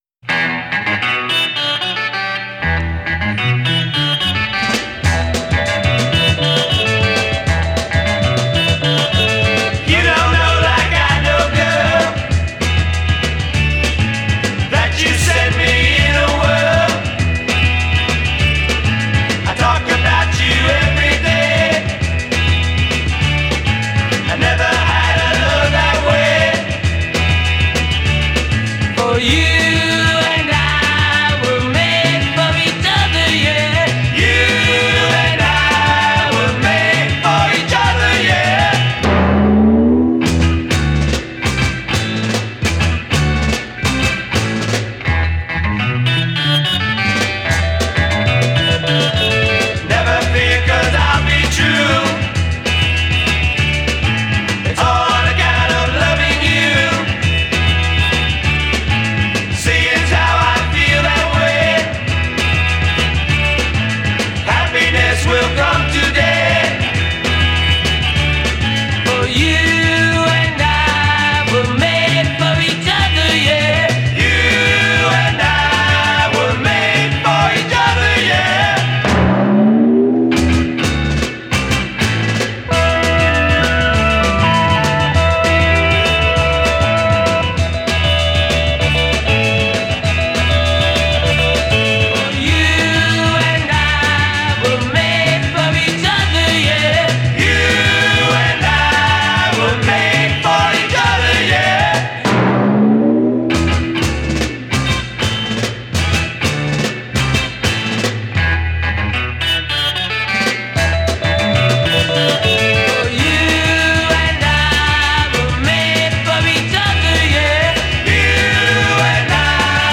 wpe1nice garagey day-trippery rock cut